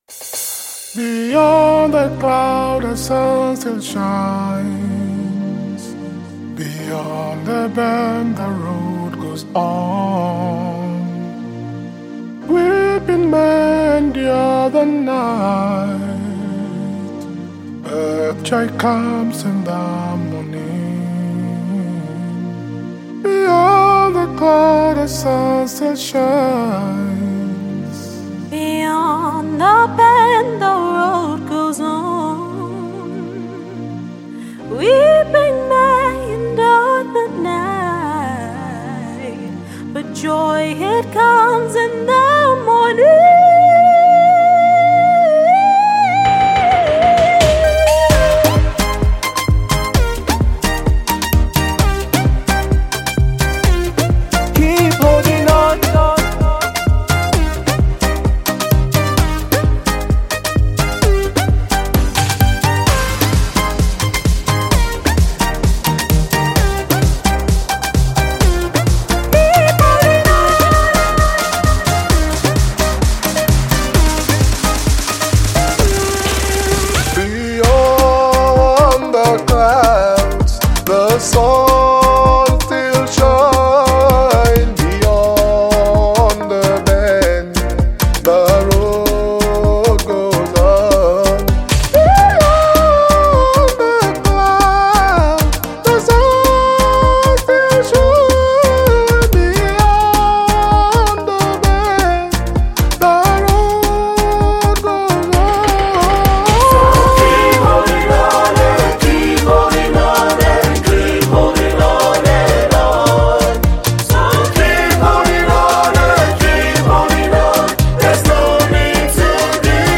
The song is a family collaboration